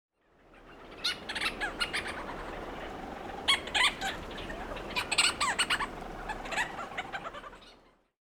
コシジロウミツバメ
【分類】 ミズナギドリ目 ウミツバメ科 オーストンウミツバメ属 コシジロウミツバメ 【分布】北海道〜本州の山地、海岸沿いの崖地など 【生息環境】大黒島や南千鳥で繁殖、それ以外の海域では冬に生息 【全長】約18〜20cm 【主な食べ物】昆虫（ハエ、アブ、ハチ、甲虫類、アリなど） 【鳴き声】地鳴き 【聞きなし】「ジリリリッ ジリリリッ」